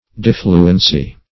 Search Result for " diffluency" : The Collaborative International Dictionary of English v.0.48: Diffluence \Dif"flu*ence\, Diffluency \Dif"flu*en*cy\, n. A flowing off on all sides; fluidity.